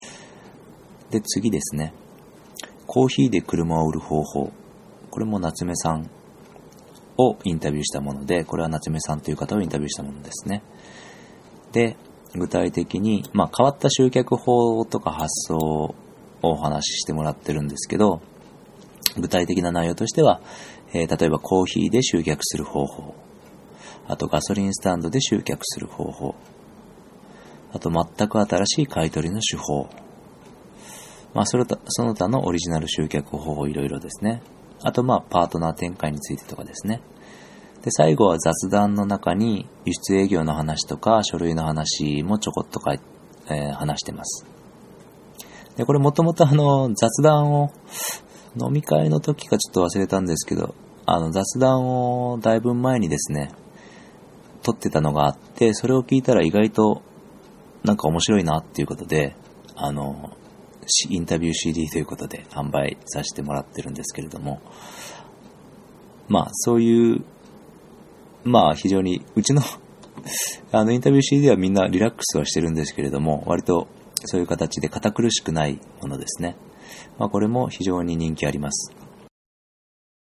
このCDの音声解説はこちら